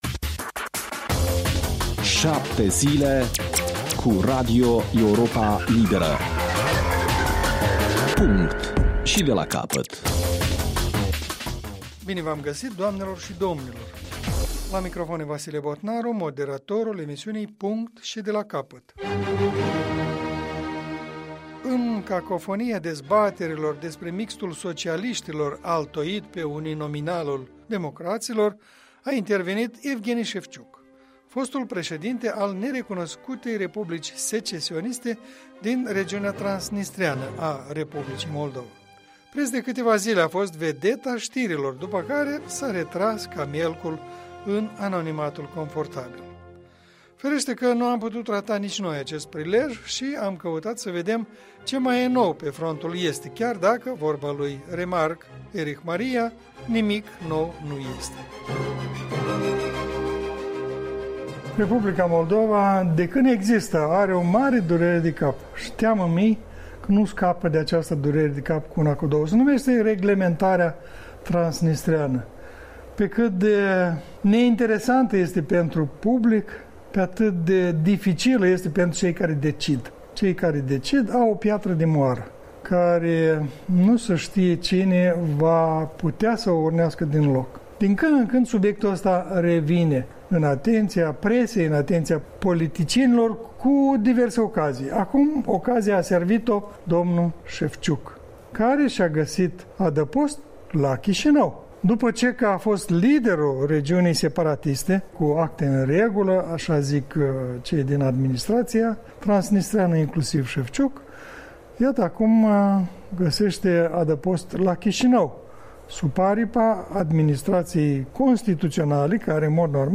dialog